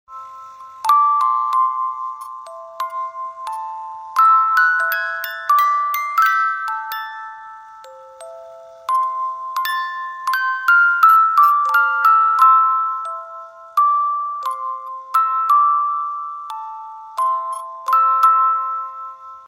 Romántico